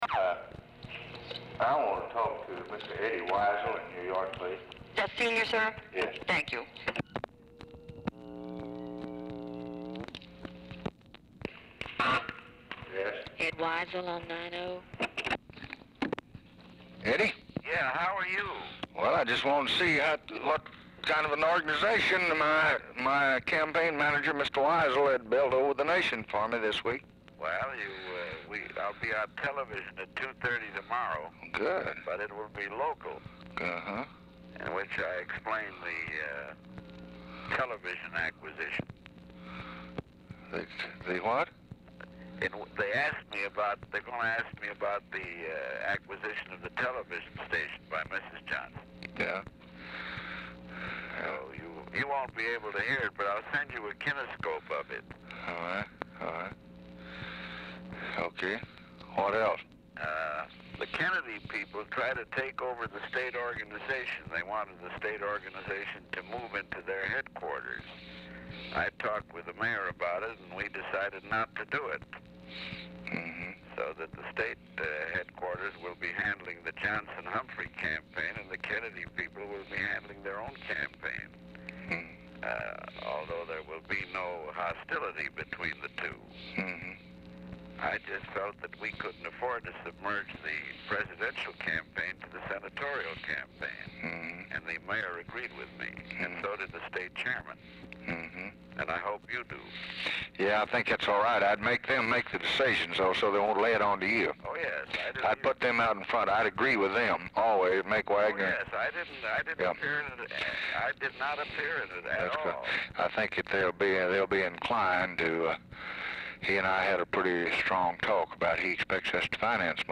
Telephone conversation
Format Dictation belt
Oval Office or unknown location